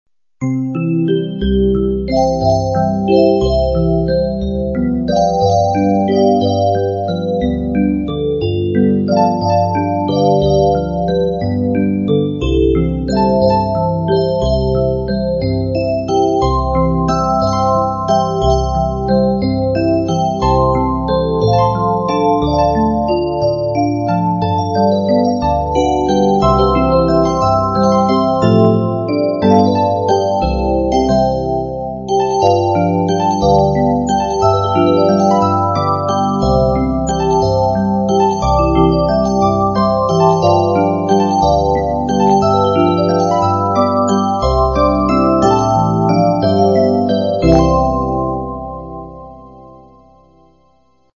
[Computer Simulation]